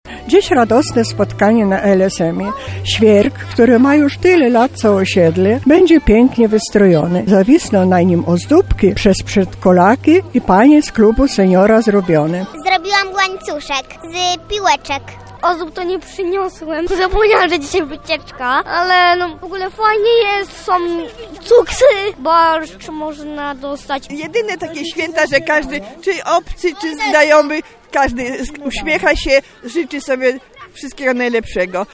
Lublinianie zebrali się na osiedlu Adama Mickiewicza, aby udekorować okazały świerk, który rośnie na placu przy fontannie.
Przystrajaniu drzewka towarzyszyło kolędowanie i radosna, rodzinna atmosfera.